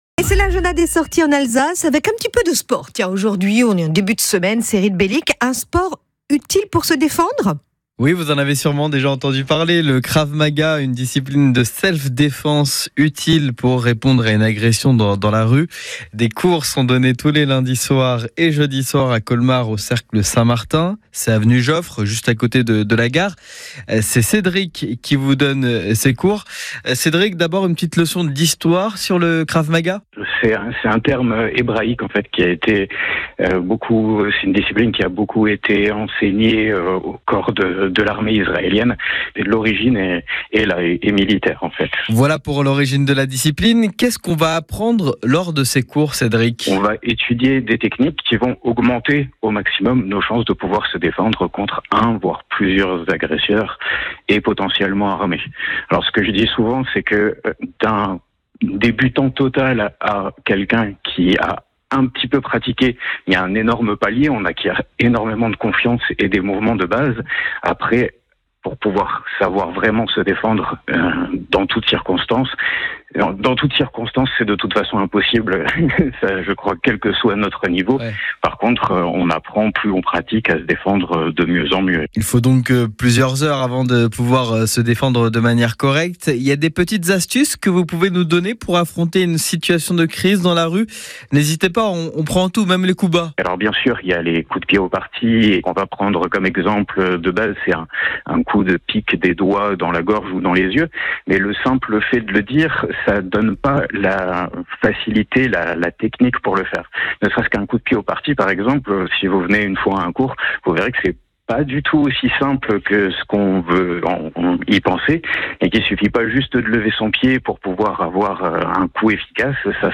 Notre enseignant vous parle des cours Interview